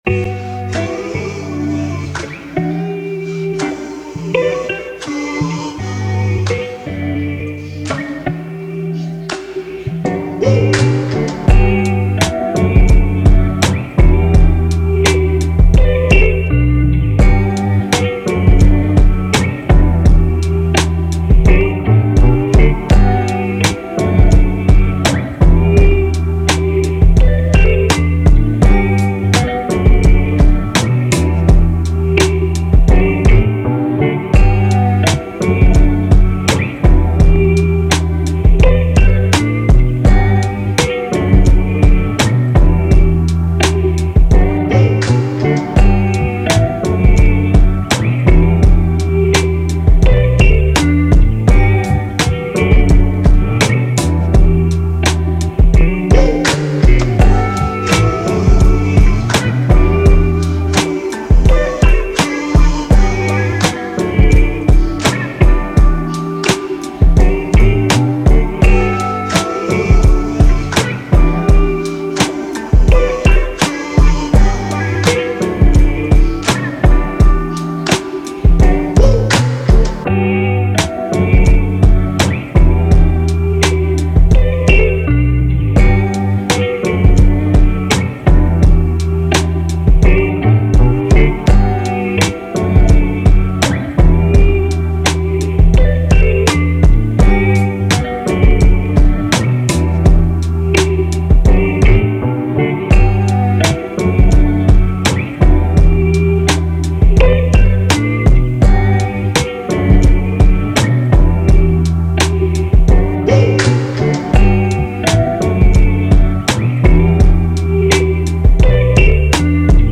R&B
D Major